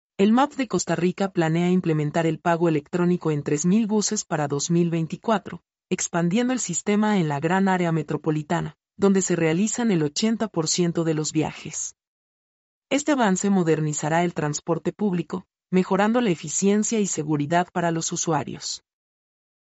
mp3-output-ttsfreedotcom-59-1.mp3